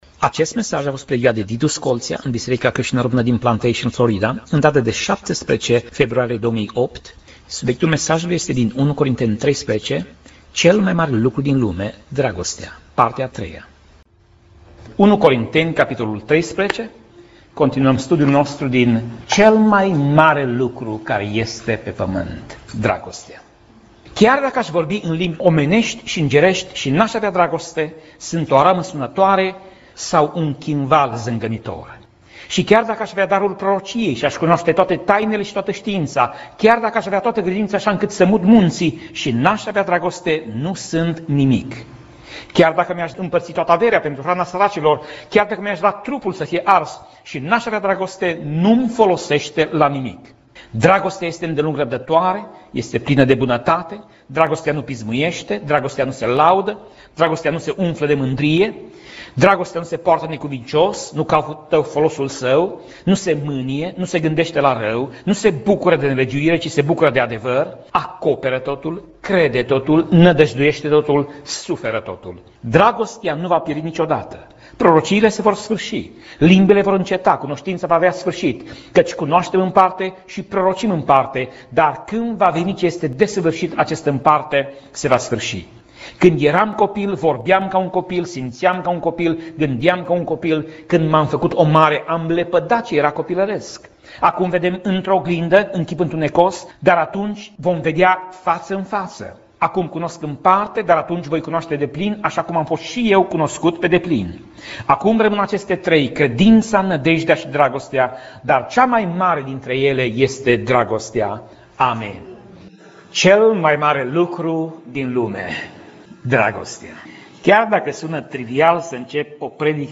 Pasaj Biblie: 1 Corinteni 13:1 - 1 Corinteni 13:7 Tip Mesaj: Predica